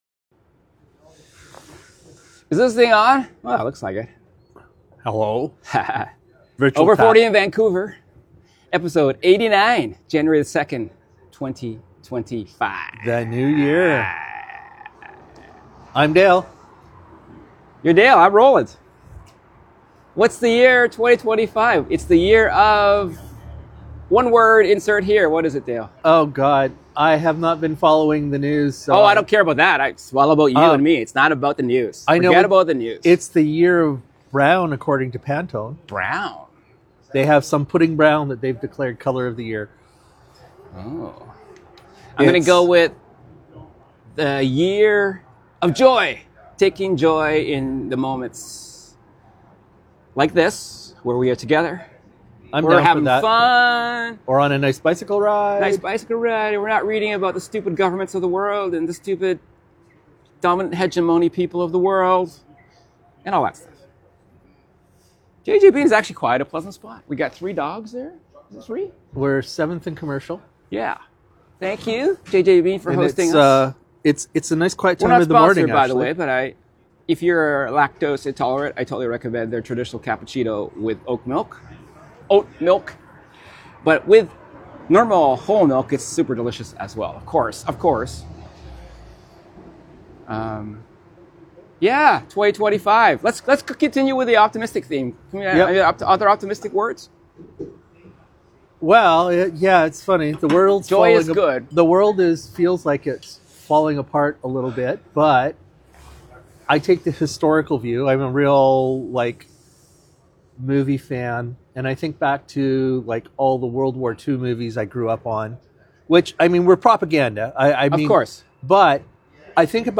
Recorded at JJ Bean 7th and Commercial eating a muffin and drinking a coffee 🙂